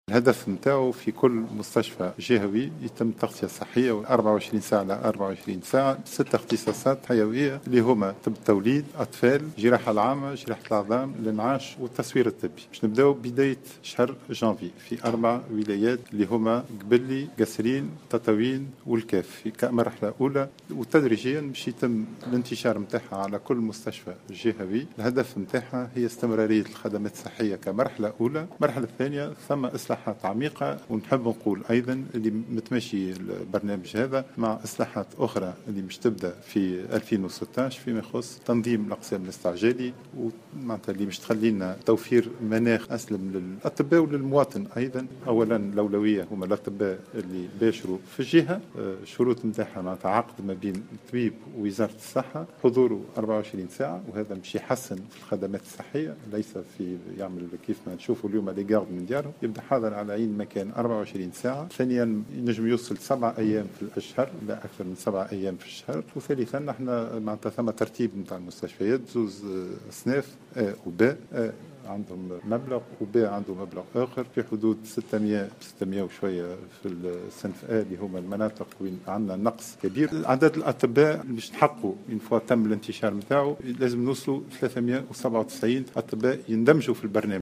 أكد وزير الصحة سعيد العايدي في تصريح اعلامي اليوم الاثنين على هامش اشرافه على لقاء إعلامي للإعلان عن تفعيل وانطلاق الخطة الوطنية لتغطية الجهات الداخلية بأطباء الاختصاص أن الهدف من هذا البرنامج هو أن يتم تعميم التغطية الصحية على كل المستشفيات الجهوية على مدى 24 ساعة على حد قوله.